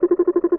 sci-fi_scan_target_03.wav